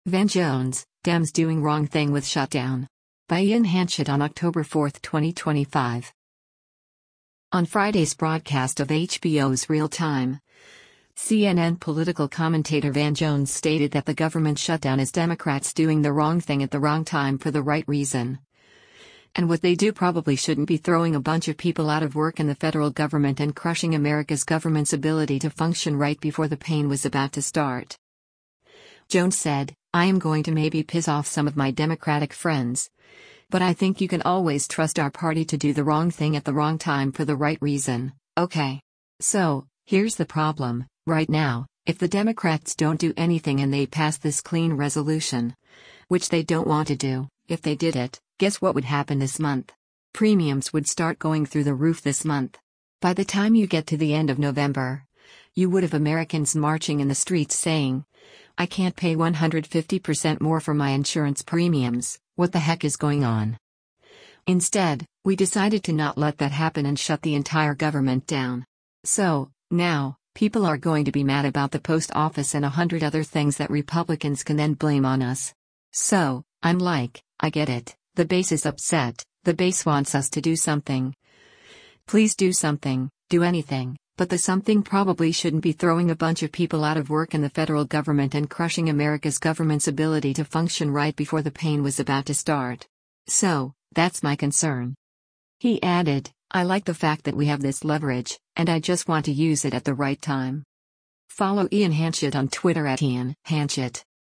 On Friday’s broadcast of HBO’s “Real Time,” CNN Political Commentator Van Jones stated that the government shutdown is Democrats doing “the wrong thing at the wrong time for the right reason,” and what they do “probably shouldn’t be throwing a bunch of people out of work in the federal government and crushing America’s government’s ability to function right before the pain was about to start.”